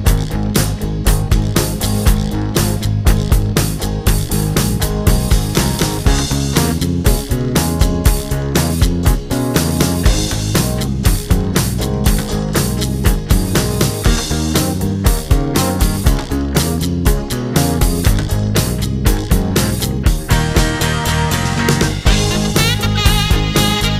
Minus Guitar Pop (1970s) 2:55 Buy £1.50